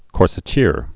(kôrsĭ-tîr)